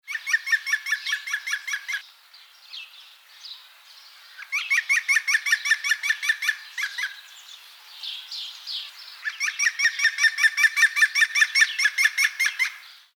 Gesang des Grünspechts ähnelt dem Lachen von Menschen und ist leicht zu erkennen.
0453_Gruenspecht_Rufreihe.mp3